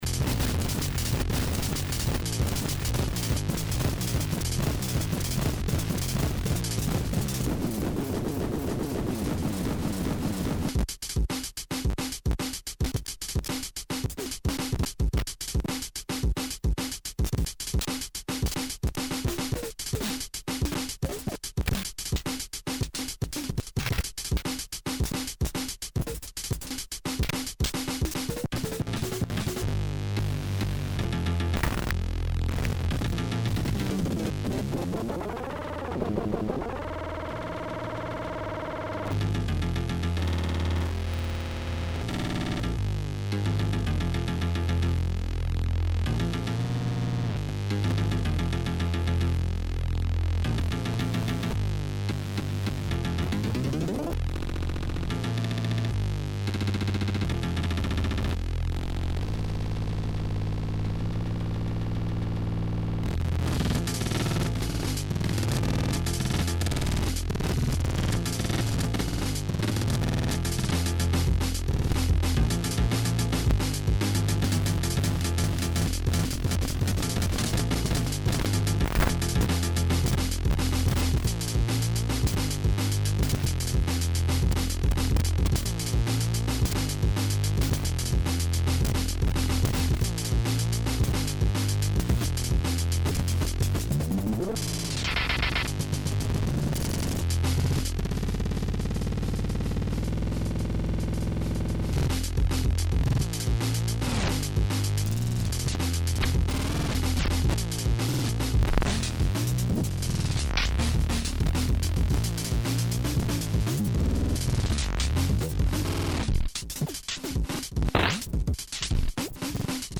SAMPLE drum loop
some of these are nearly 20mg - please watch your volumes there are some very loud sections in these. the samples are just me cutting crude loops of mine and other peoples work, computer - teaspoon - mixer - computer, nothing else. at a few points i plug in the attenuated audio output of a summed pair of sine VCO’s set to low frequencies into the CV input to simulate the audio outputs of a standard sound card, no other outside control is used. i try to cover as much range as i can without stopping on anything for too long so you will have to use your imagination to pause things a bit, there is a lot to cover. most tracks have the right side clean so you can compare the two. most of the time is spent with the SZ, FDBK, LNGTH and THRSH controls, the push button is only used a few times, tilt disabled.